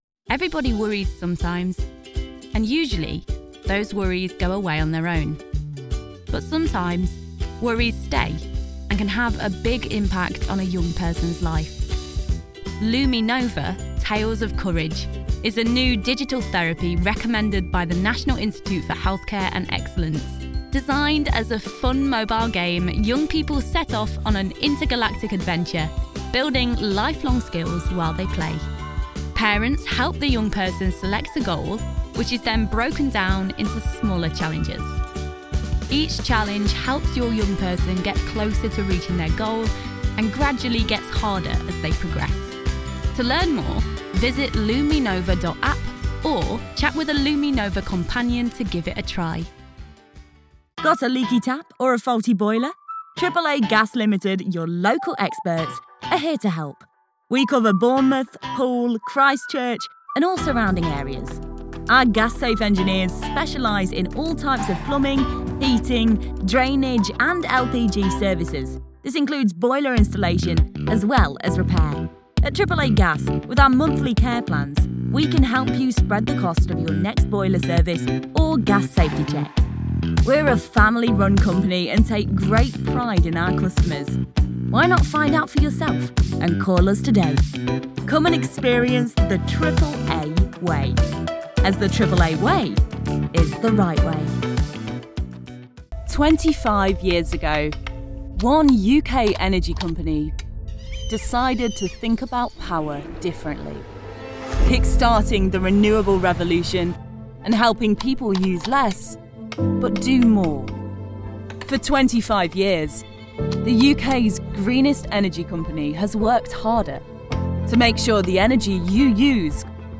Englisch (Britisch)
Verspielt, Cool, Vielseitig
Unternehmensvideo